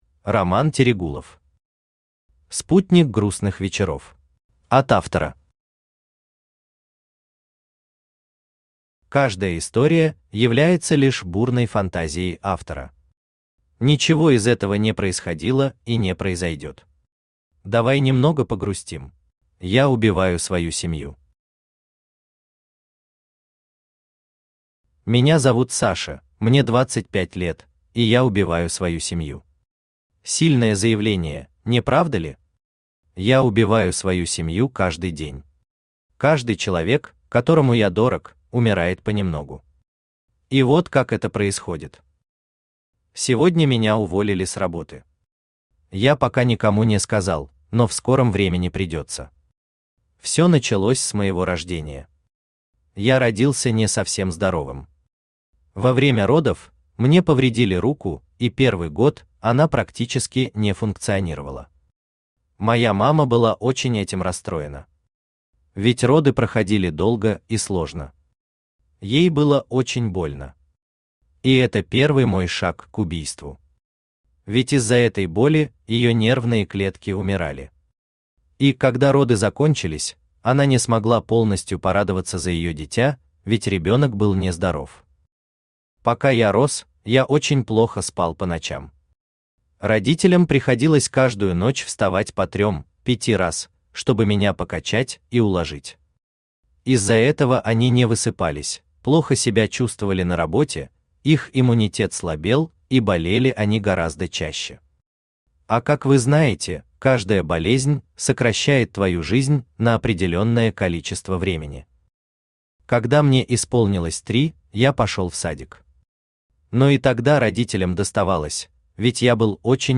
Аудиокнига Спутник грустных вечеров | Библиотека аудиокниг
Aудиокнига Спутник грустных вечеров Автор Роман Ирекович Терегулов Читает аудиокнигу Авточтец ЛитРес.